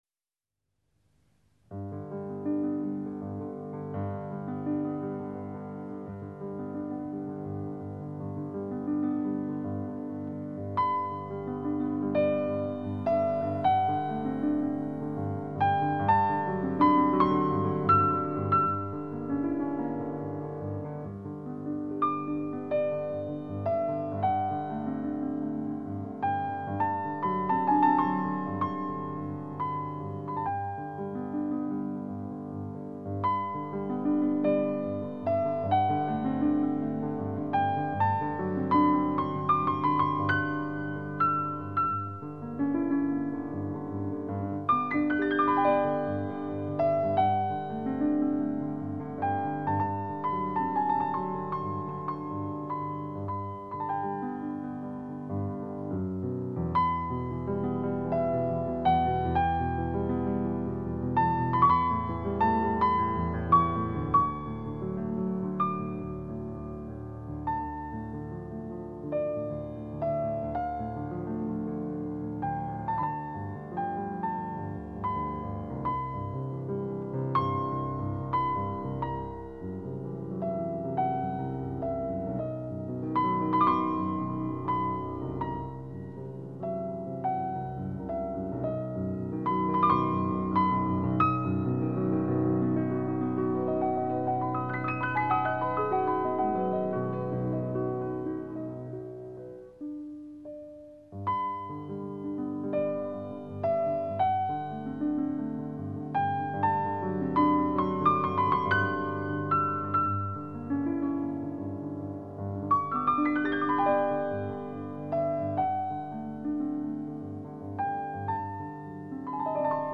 的确很有味道！